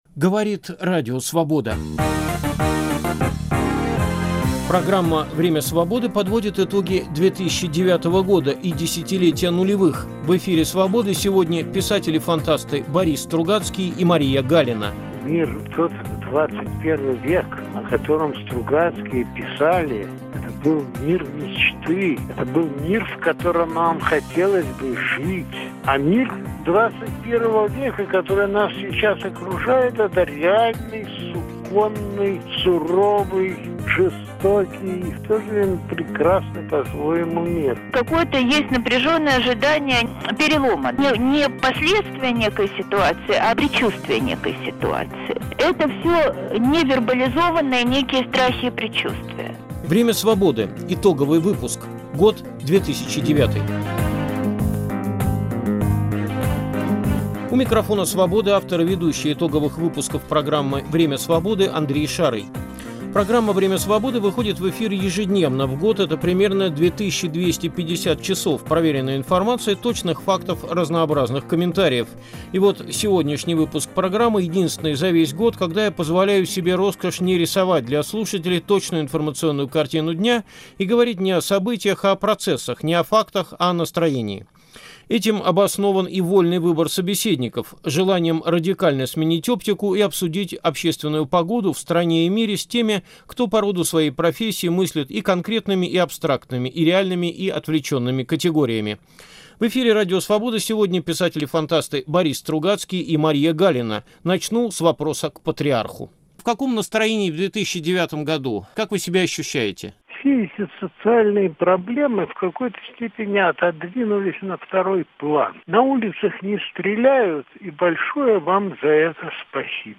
В эфире Радио Свобода писатели-фантасты Борис Стругацкий и Мария Галина подводили итоги уходящего 2009 года и делились с радиослушателями своими мыслями о том, как будет выглядеть будущее. В том числе - о том, почему его четкие контуры определить невозможно.